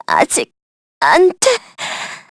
Jane_L-Vox_Dead_kr.wav